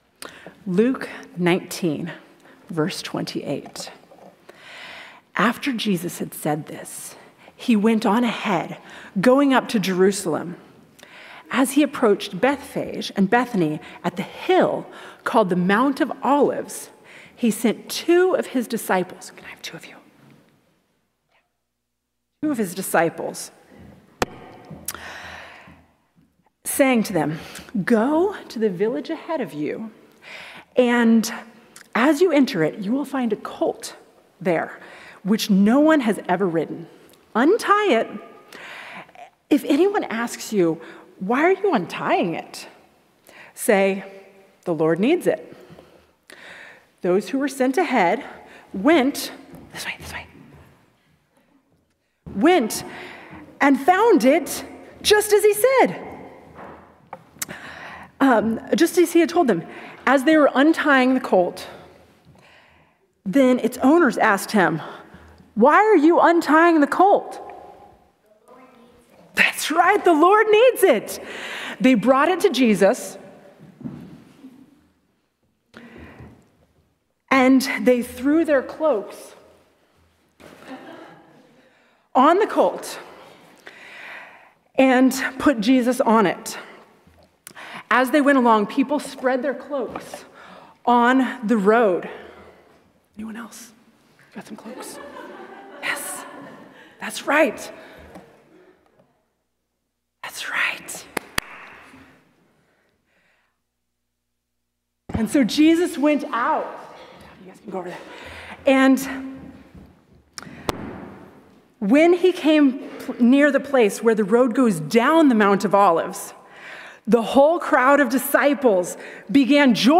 Sermons | Washington Community Fellowship